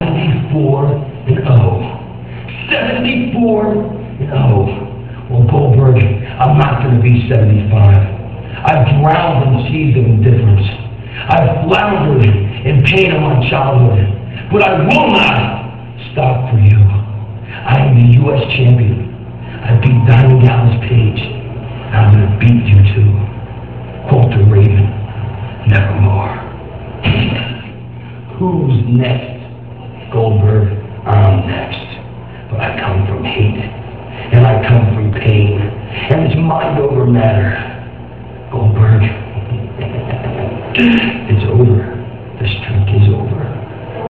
- These speeches come from WCW Monday Nitro - [4.20.98] - These are the 2 video promos Raven cut for the match against Goldberg where he lost the U.S. title to him. - (0:44)